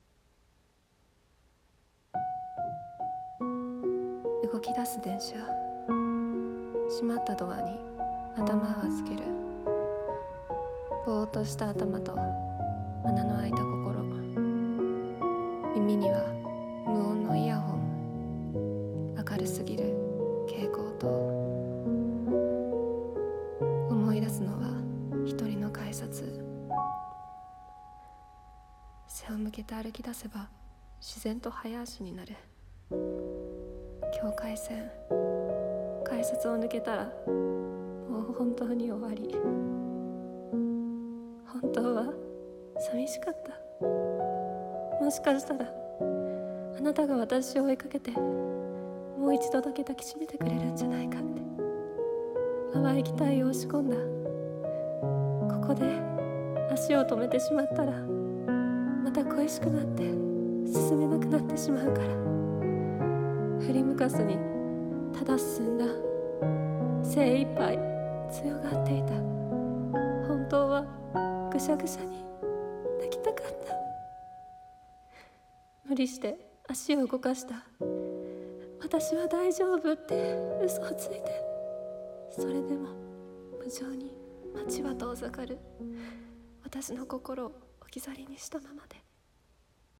声劇 改札